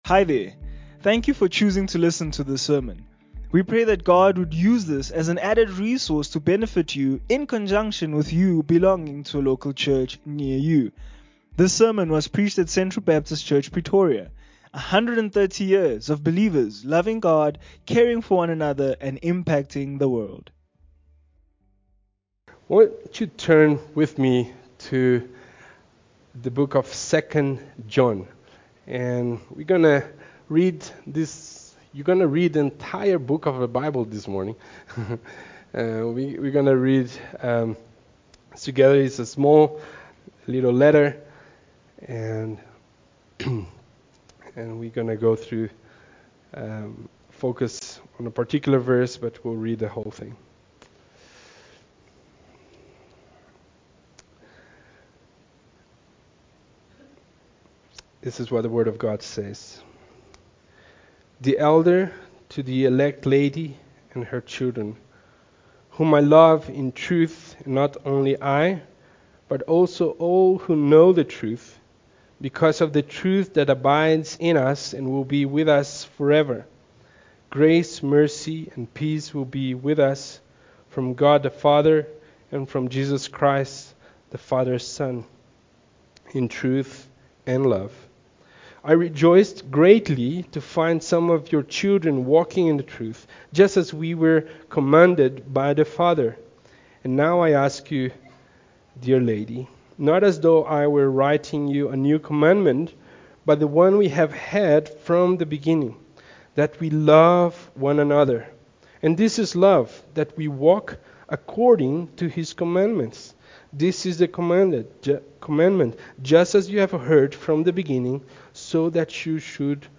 Sermons - Central Baptist Church Pretoria